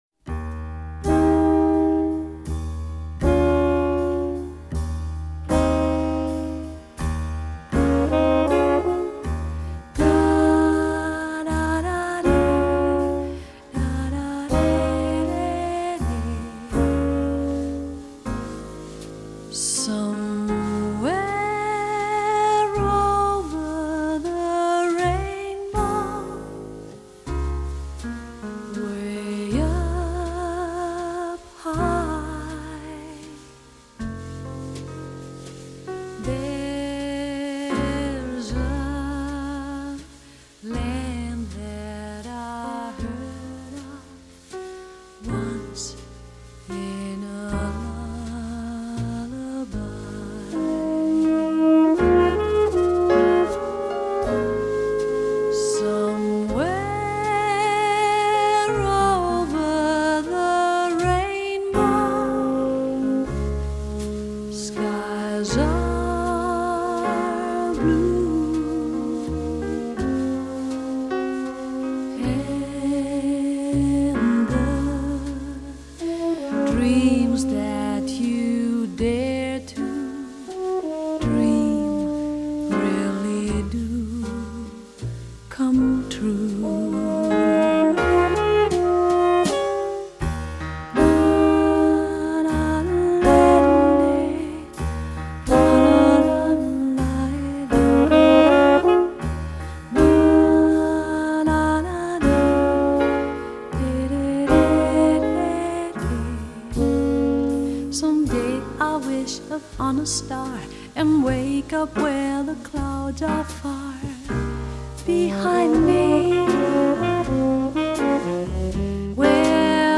细腻深邃的嗓音！